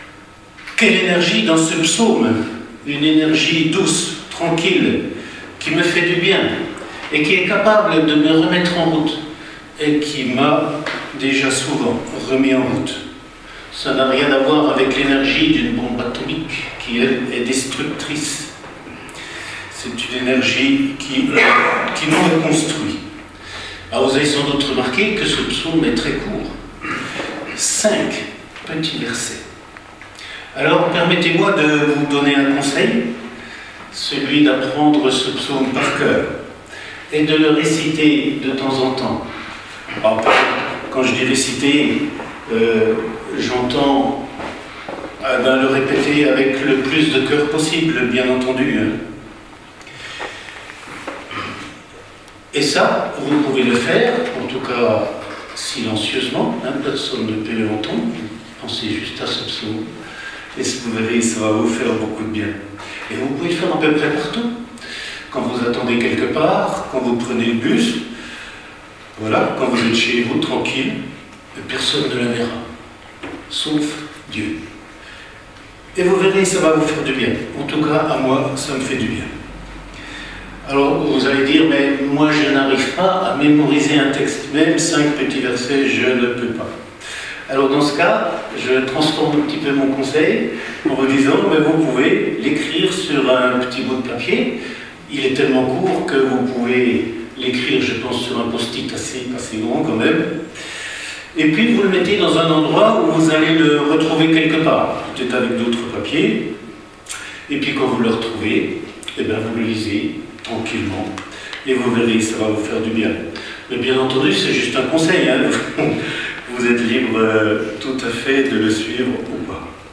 Église Mennonite dans les Vosges
Les messages 2025 au format mp3